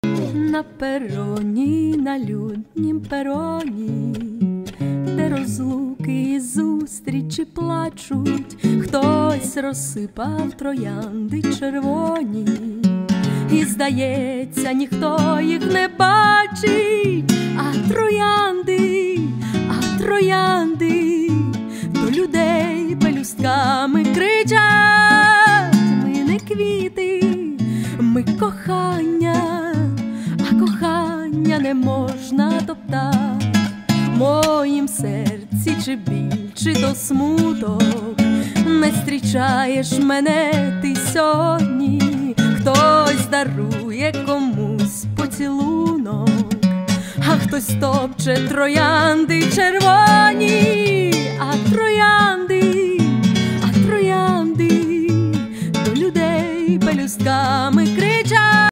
• Качество: 320 kbps, Stereo
кавер